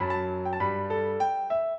piano
minuet2-9.wav